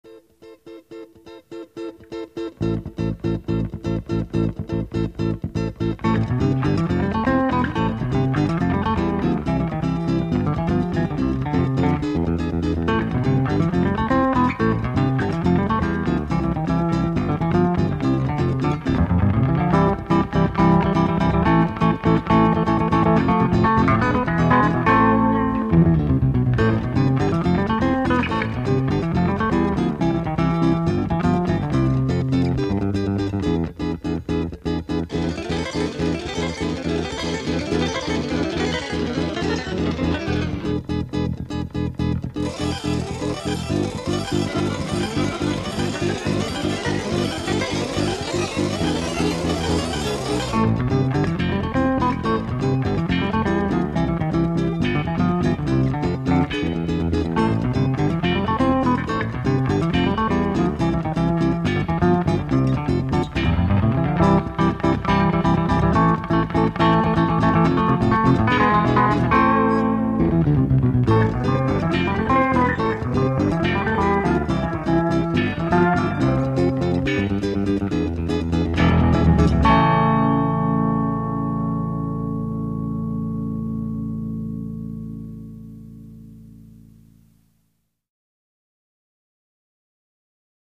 gone surf, gone all e-bass, gone Bulgarian.